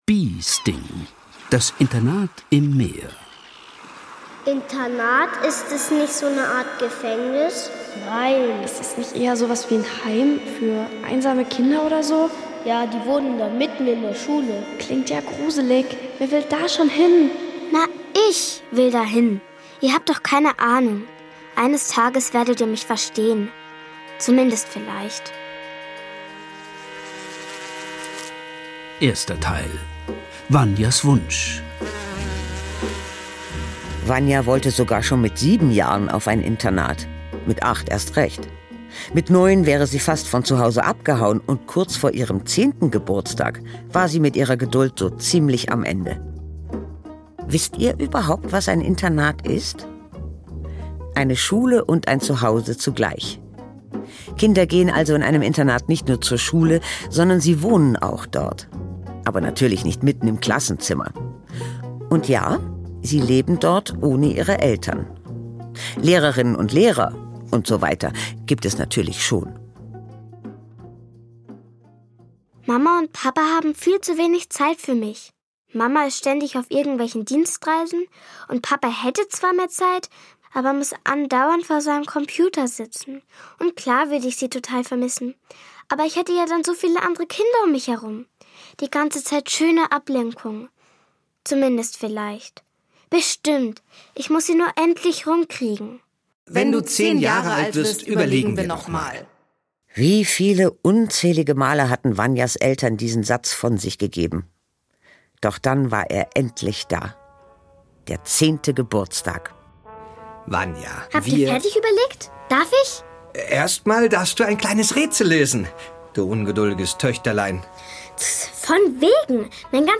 Kinderhörspiel Bee Sting - Das Internat im Meer ~ Hörspiele, Geschichten und Märchen für Kinder | Mikado Podcast